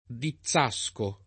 [ di ZZ#S ko ]